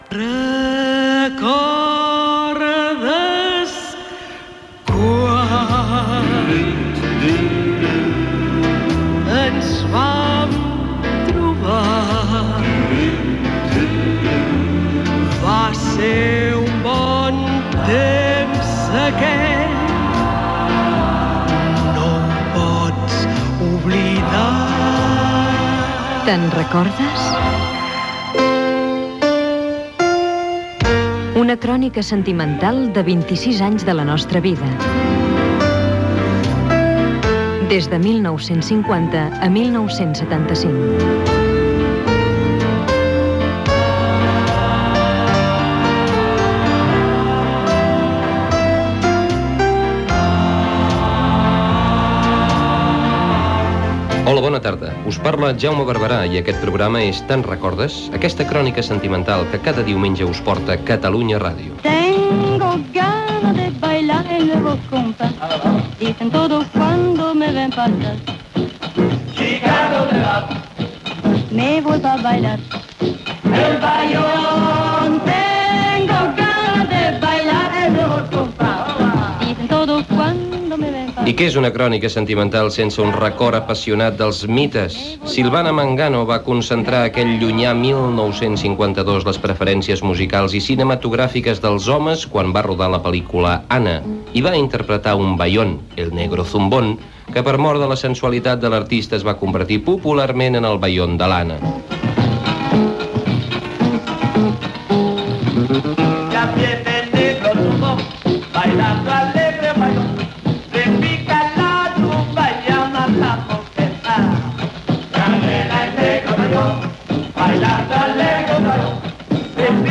98bfdcbe5d99a2511cb4c5e99dda5f1d02186cd5.mp3 Títol Catalunya Ràdio Emissora Catalunya Ràdio Cadena Catalunya Ràdio Titularitat Pública nacional Nom programa Te'n recordes? Descripció Careta del programa, espai dedicat a l'any 1952. S'hi parla de l'actiu Silvana Mangano i de la cartilla de racionament.